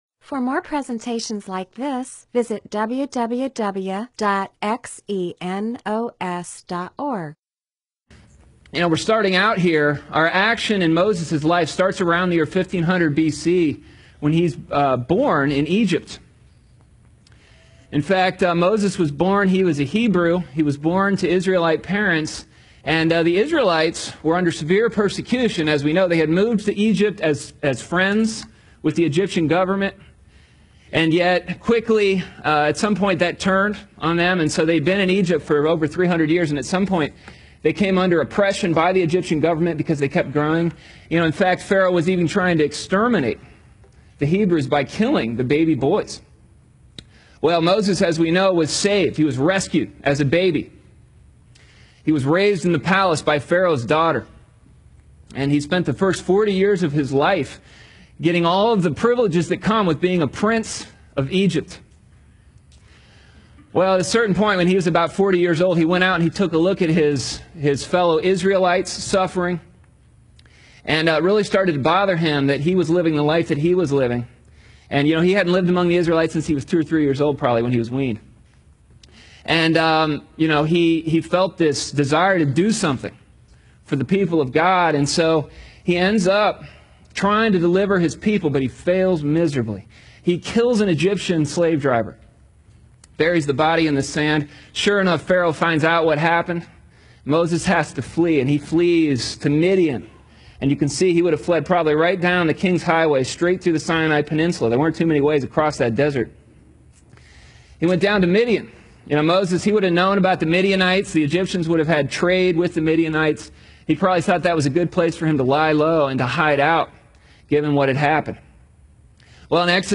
MP4/M4A audio recording of a Bible teaching/sermon/presentation about Exodus 2-4.